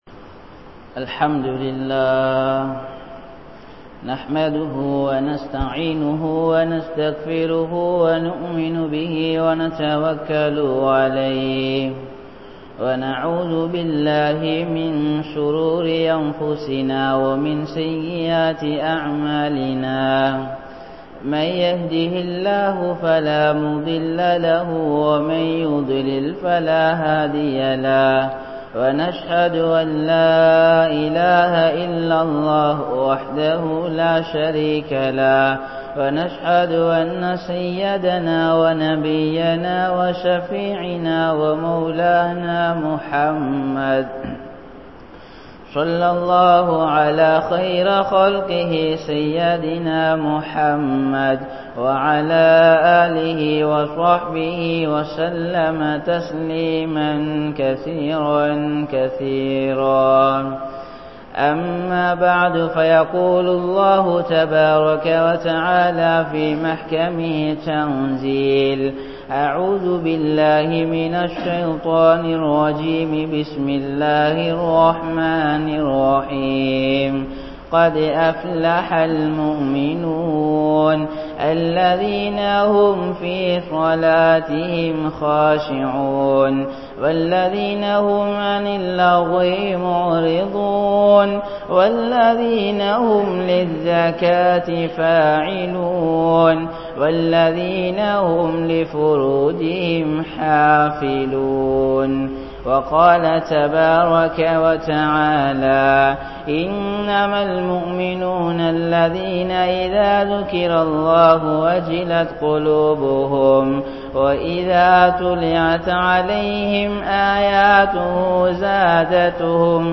Mumeenin Panpuhal (முஃமினின் பண்புகள்) | Audio Bayans | All Ceylon Muslim Youth Community | Addalaichenai